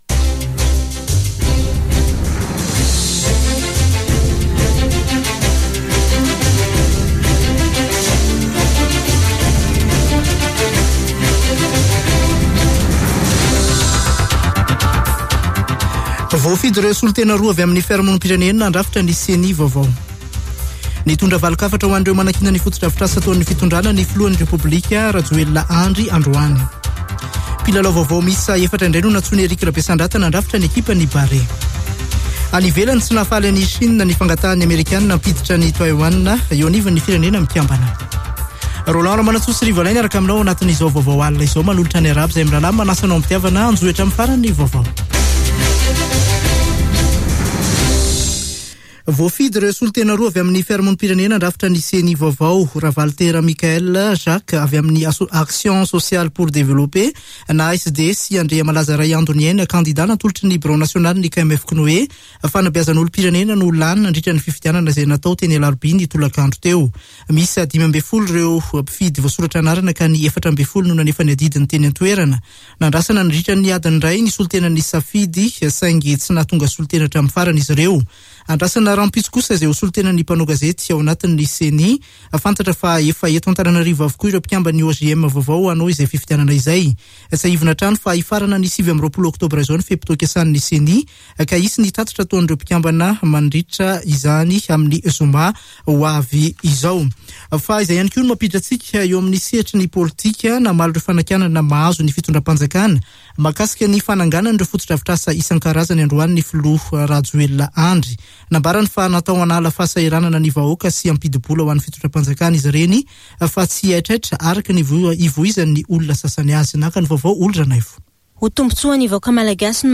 [Vaovao hariva] Alarobia 27 oktobra 2021